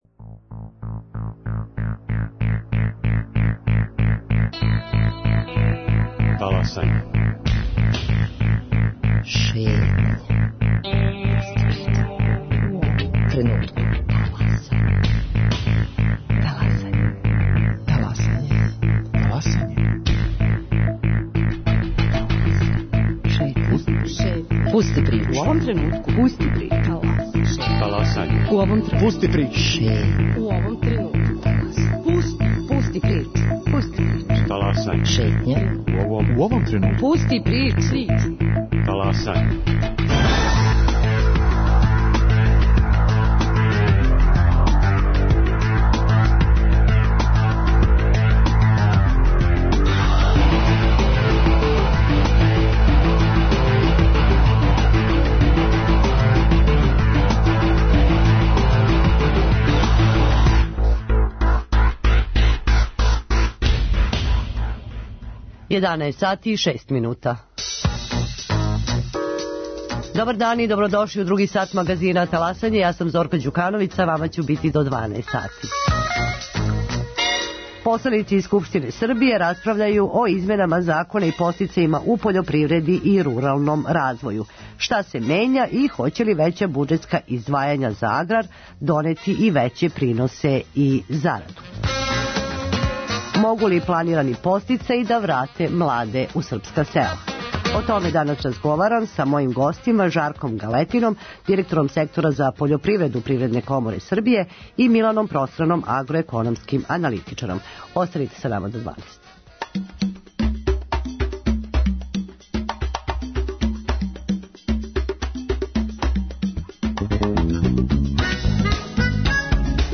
агроекономски аналитичар